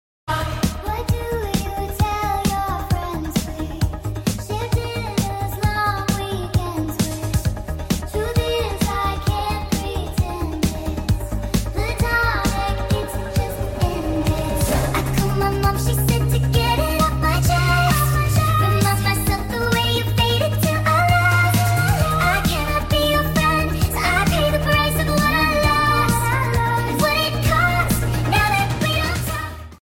Sped up!